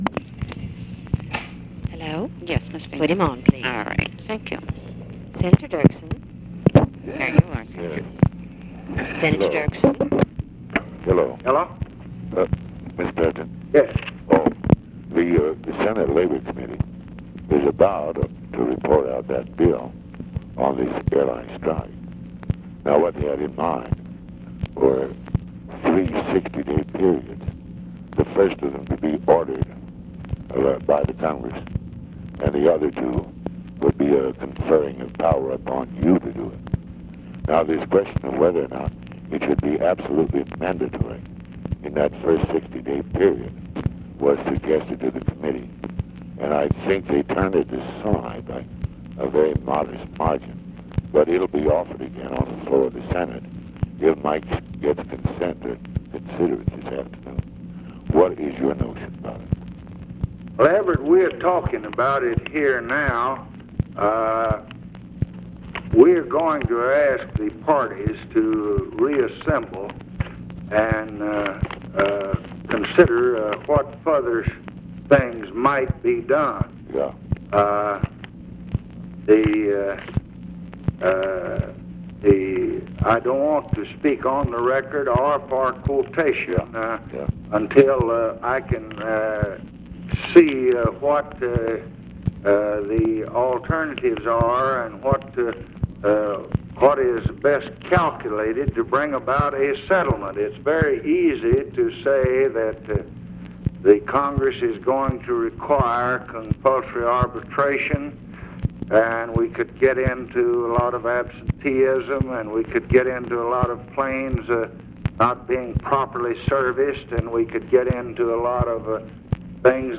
Conversation with EVERETT DIRKSEN, August 1, 1966
Secret White House Tapes